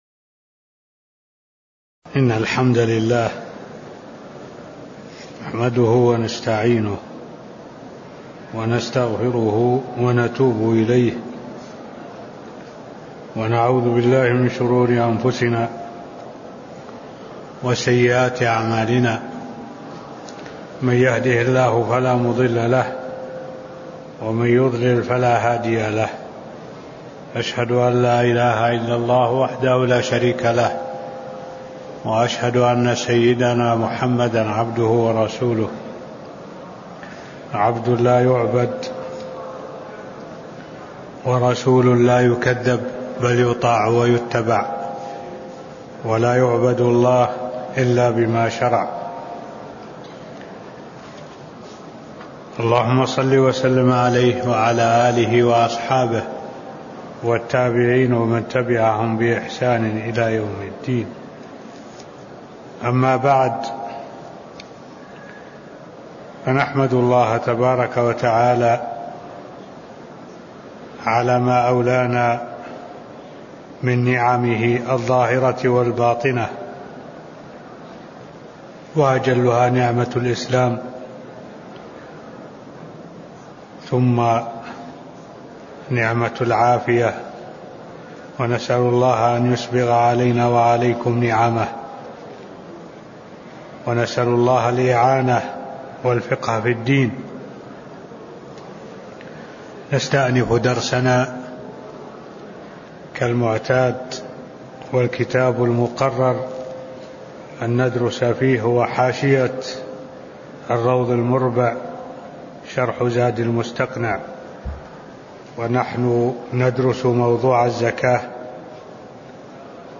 تاريخ النشر ٢٠ محرم ١٤٢٧ هـ المكان: المسجد النبوي الشيخ: معالي الشيخ الدكتور صالح بن عبد الله العبود معالي الشيخ الدكتور صالح بن عبد الله العبود تفاصيل احكام الزكاة (النصاب) ص 175 (004)